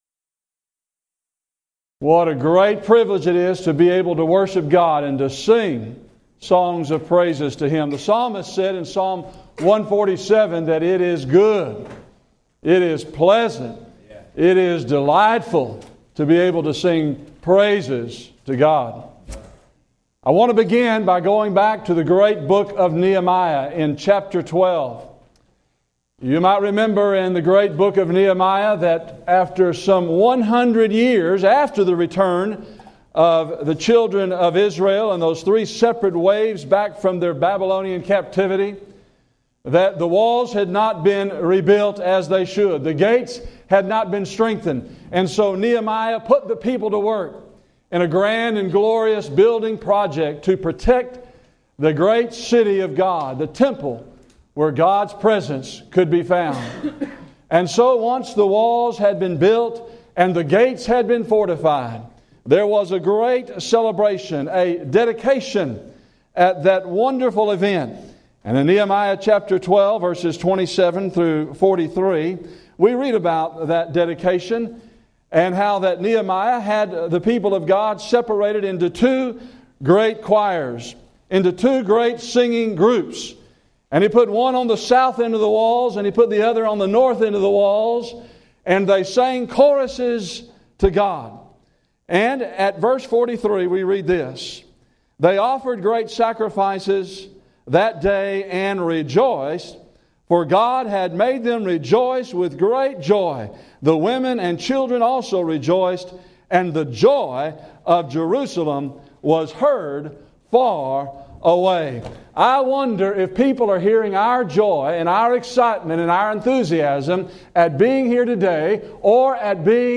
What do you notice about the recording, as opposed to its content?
Preacher's Workshop